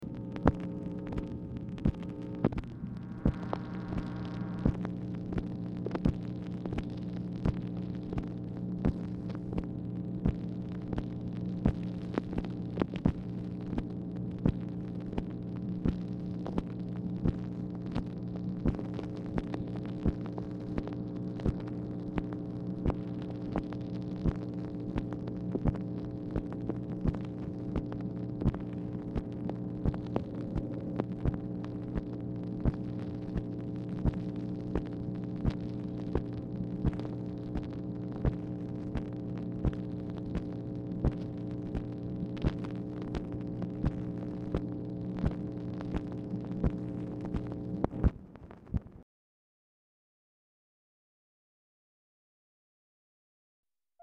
Telephone conversation # 7370, sound recording, MACHINE NOISE, 4/27/1965, time unknown | Discover LBJ
Format Dictation belt
White House Telephone Recordings and Transcripts Speaker 2 MACHINE NOISE